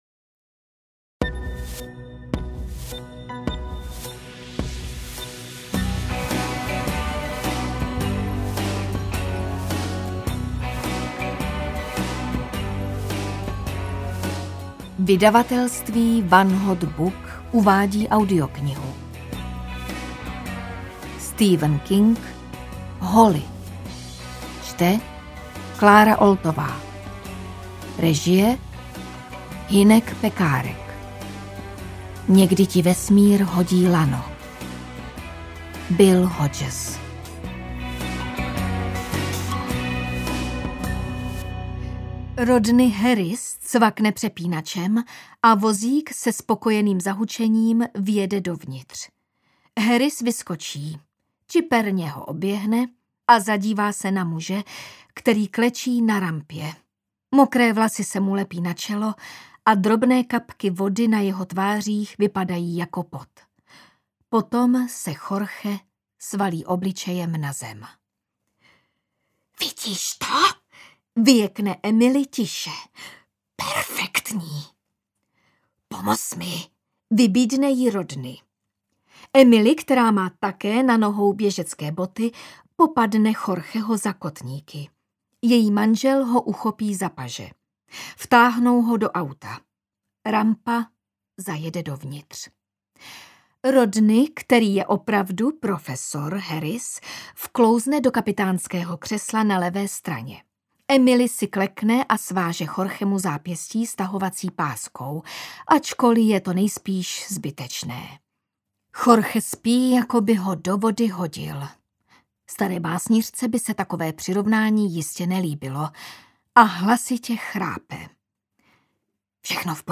Holly audiokniha
Ukázka z knihy